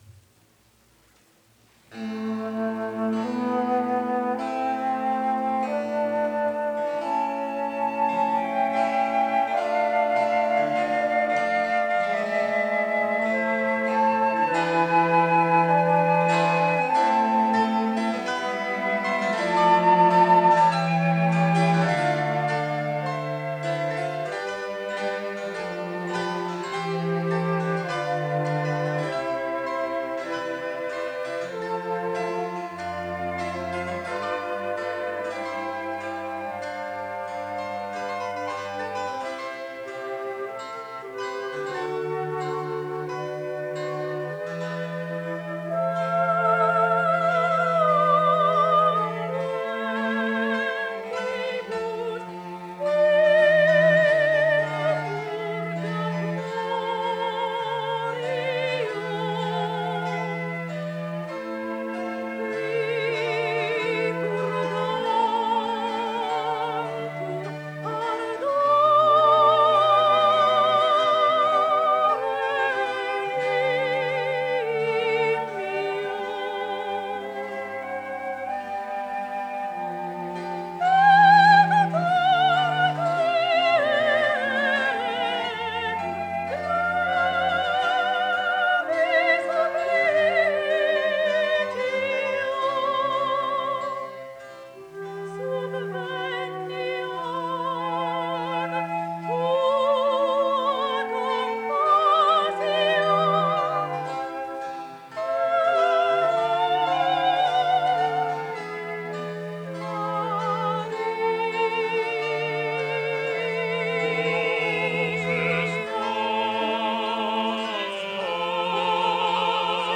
Martha Angelici, soprano – Solange Michel, mezzo-soprano – Soloists and Chorus of The French National Orchestra
broadcast recording
24 February 1704 was a French Baroque composer during the reign of Louis XIV .